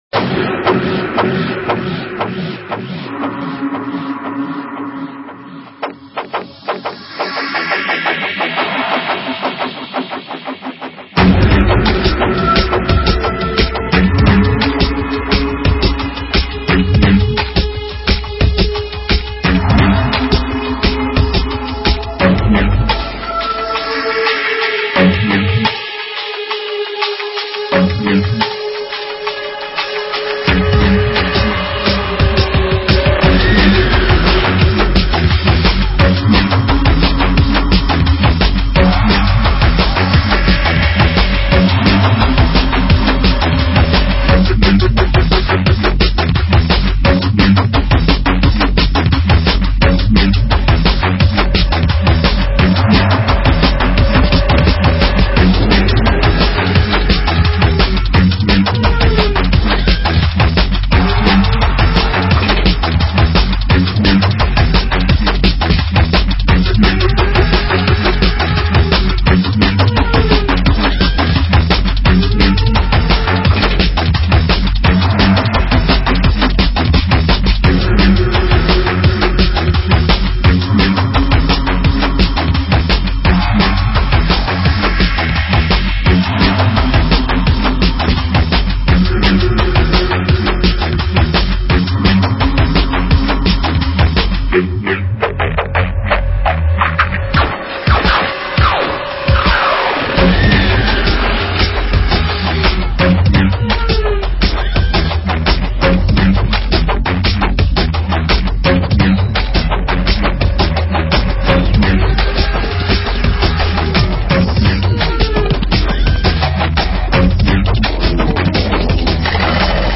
DNB
D.N.B неплохой :):)